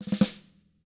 TAM3RUFF D-R.wav